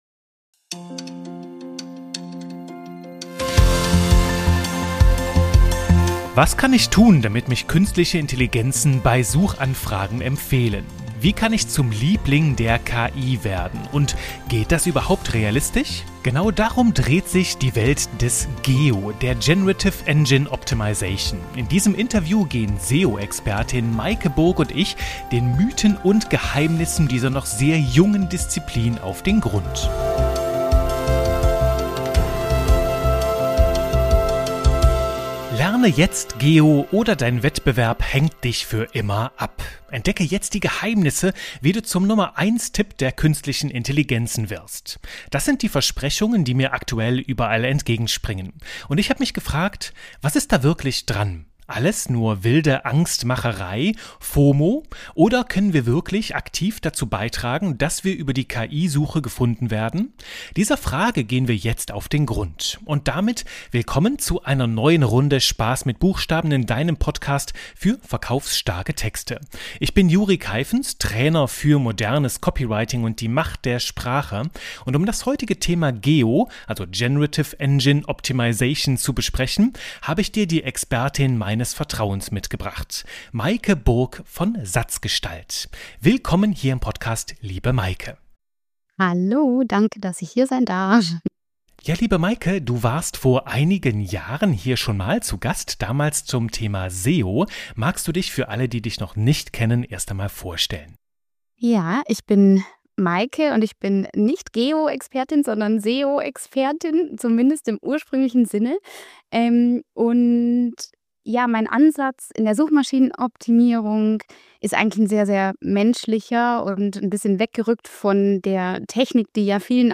233 | GEO-Basics: Was kann ich tun, um bei der KI-Suche gefunden zu werden? Interview